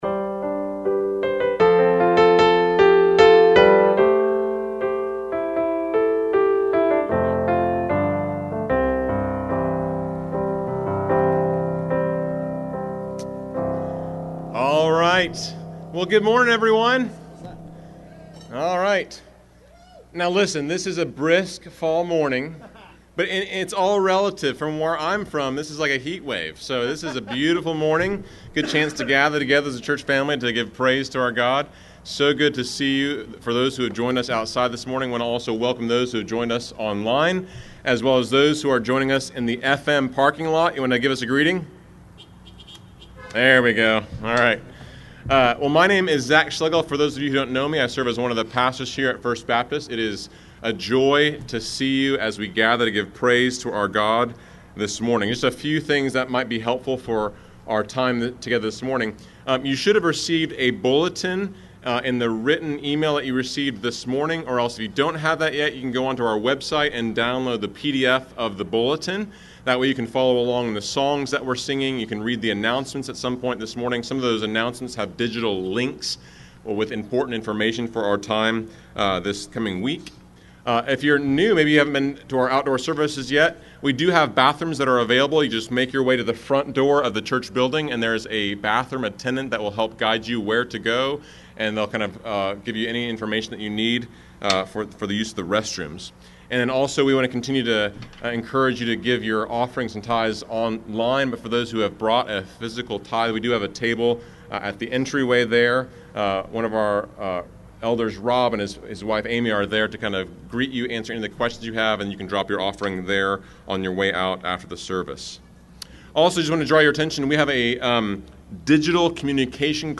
Live-Stream_wIntro_-Rich-Toward-God.mp3